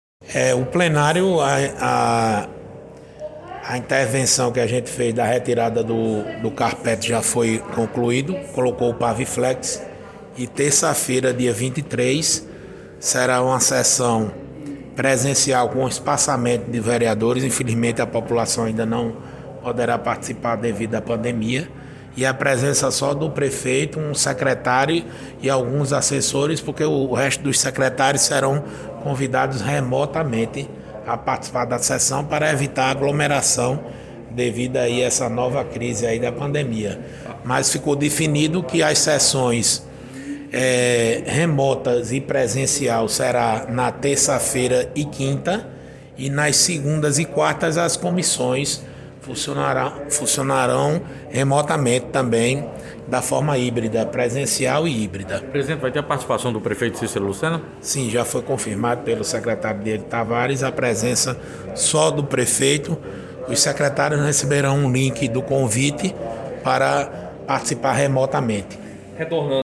Abaixo a sonora do presidente da CMJP, Dinho.